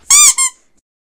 squeakyToy.ogg